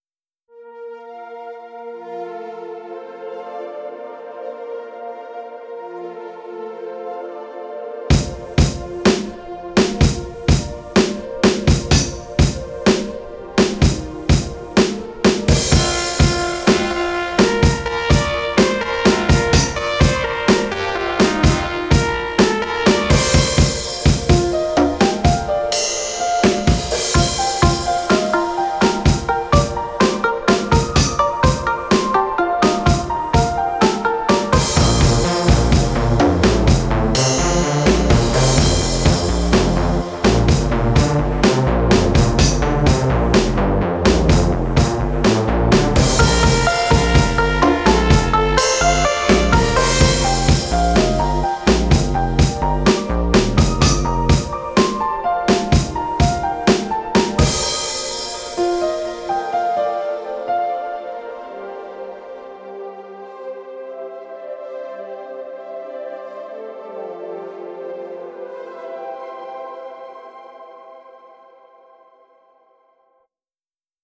music / ELECTRO DEF